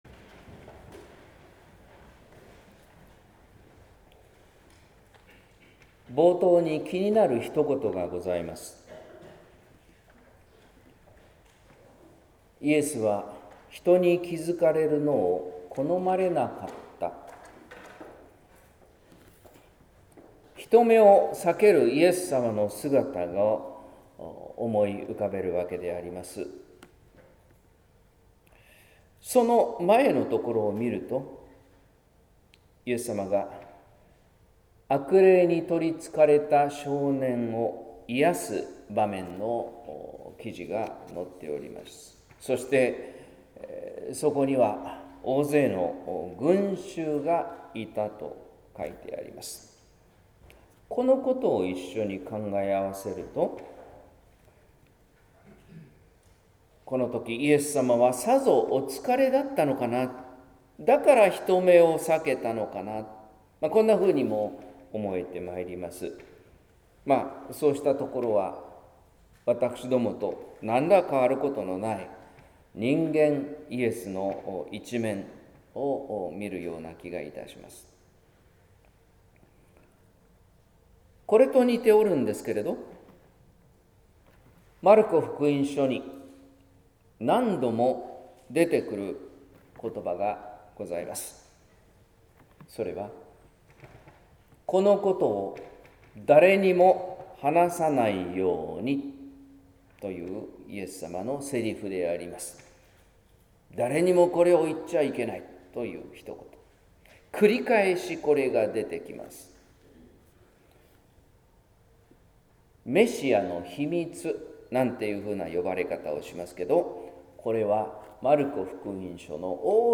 説教「目立たない尊い教え」（音声版）